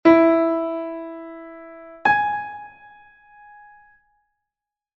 O intervalo mi3 lab4